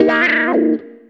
H211GUITAR.wav